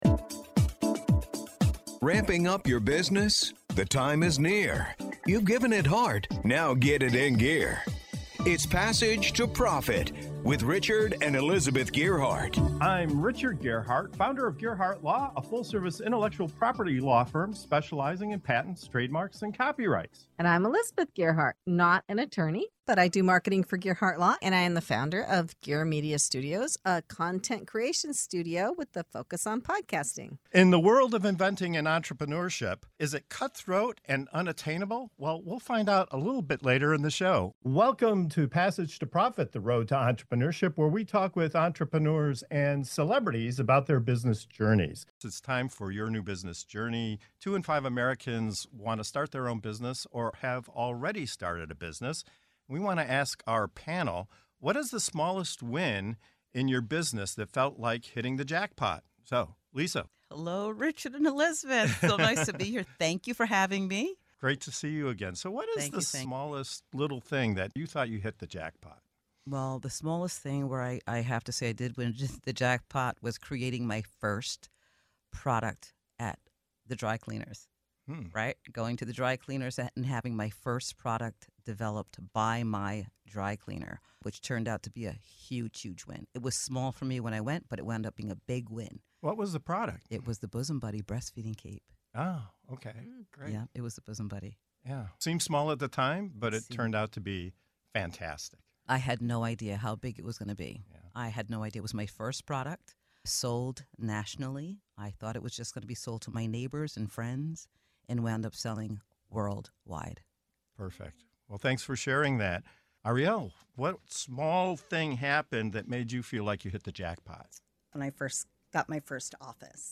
In this segment of "Your New Business Journey" on Passage to Profit Show, the panel dives into one of the most rewarding parts of entrepreneurship—those small but mighty wins that make you feel like you’ve hit the jackpot.